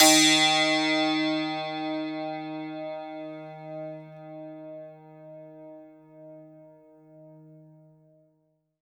52-str01-sant-d#2.wav